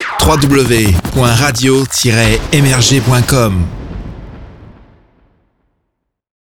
Ils sont réalisés et produits par nos équipes en interne.
JINGLE_MRG_-_RADIO-MRGCOM_2.wav